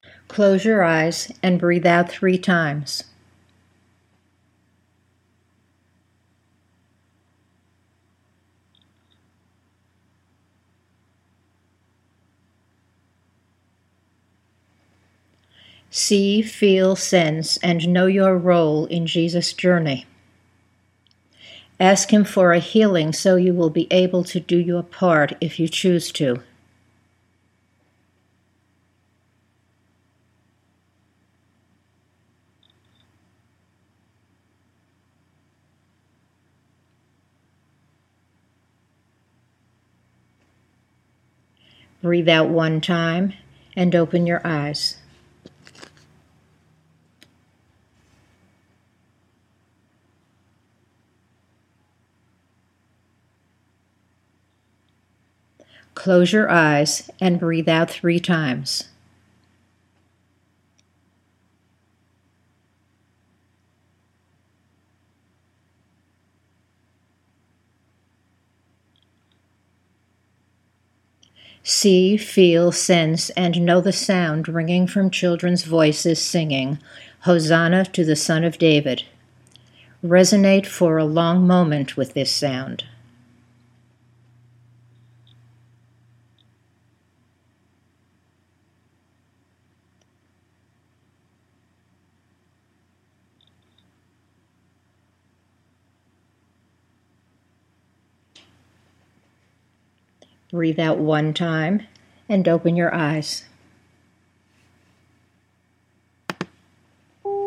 Note:  Where there are two exercises to be done together, there is a space of about ten seconds between them on the tape.